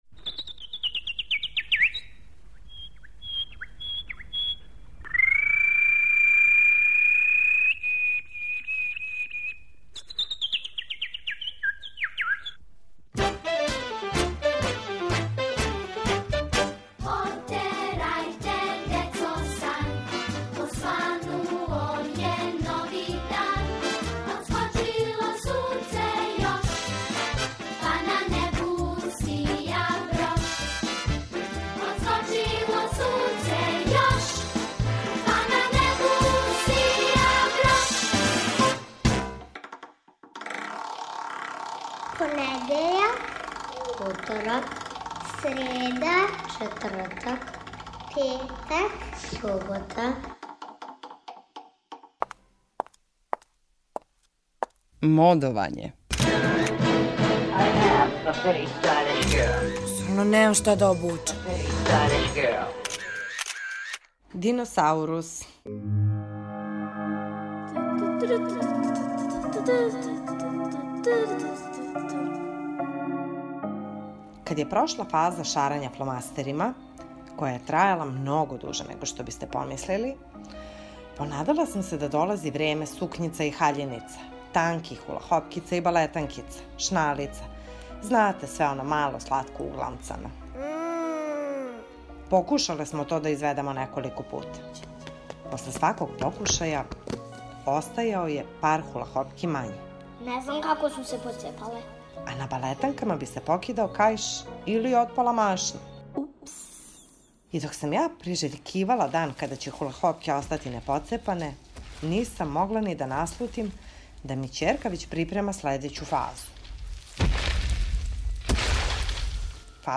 У серијалу о моди, једна професионална шминкерка прича о "диносаурус фази" своје ћерке.